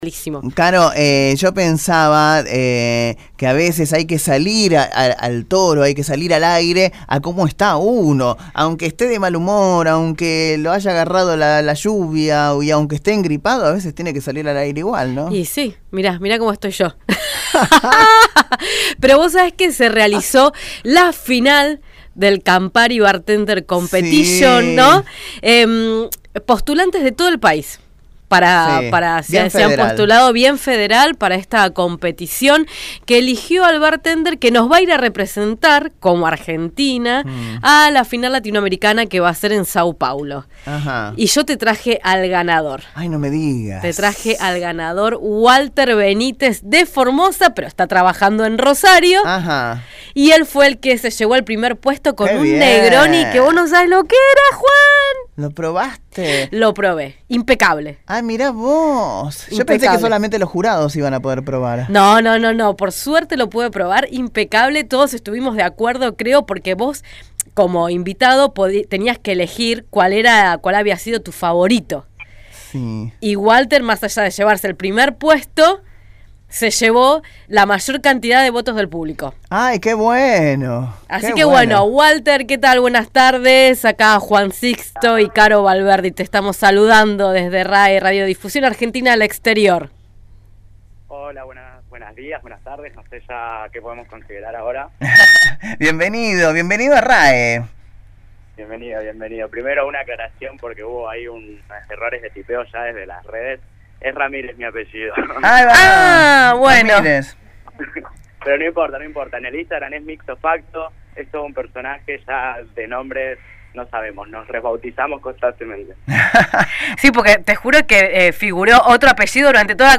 entrevista-castellano.-mp3.mp3